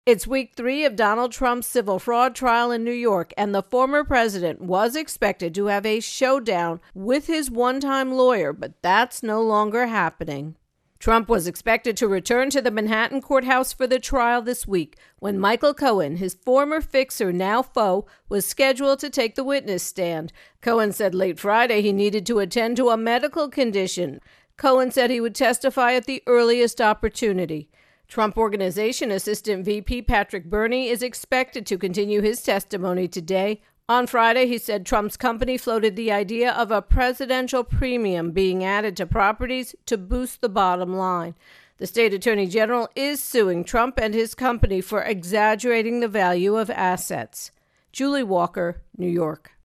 reports on Trump Fraud Lawsuit